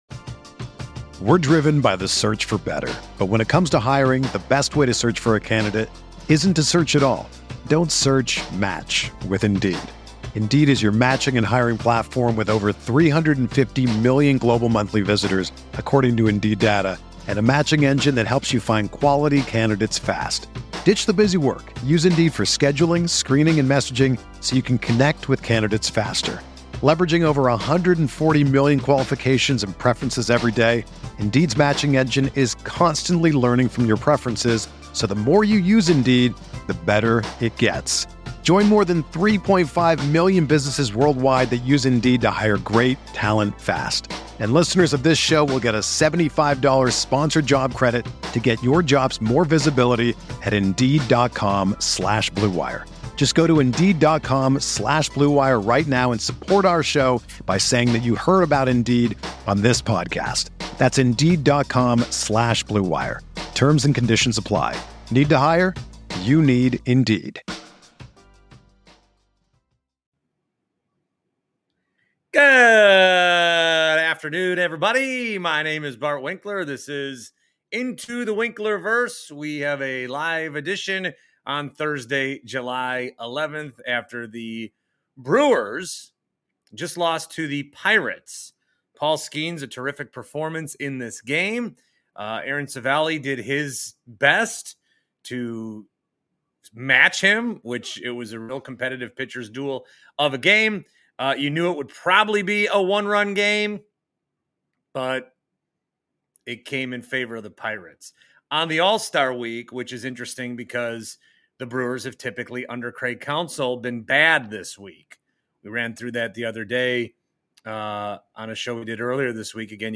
grandfather clock